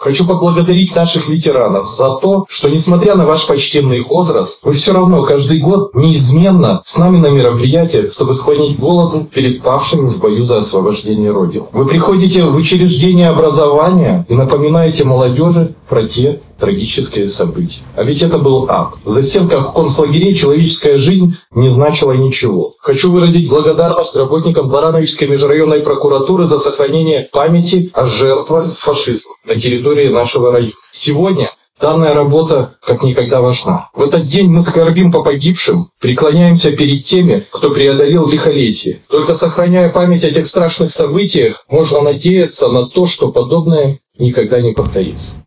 В преддверии значимой даты на станции «Барановичи-Центральные» состоялся митинг.